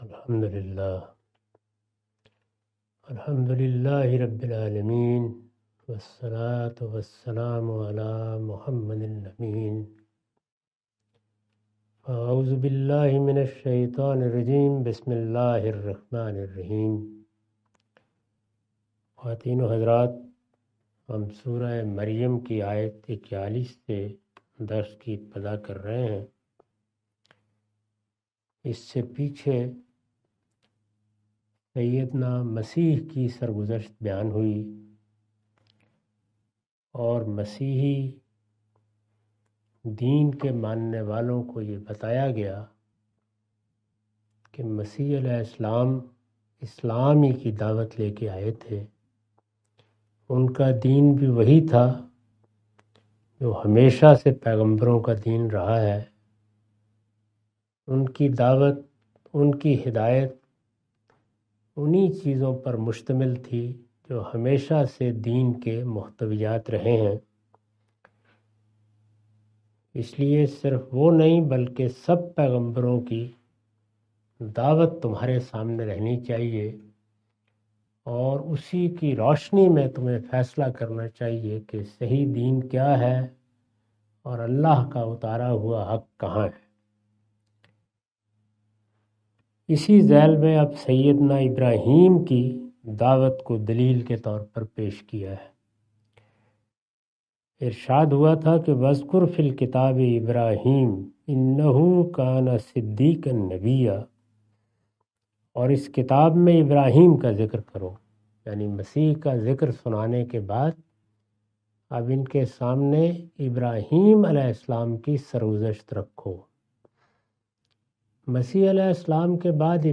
Surah Maryam A lecture of Tafseer-ul-Quran – Al-Bayan by Javed Ahmad Ghamidi. Commentary and explanation of verses 41-45.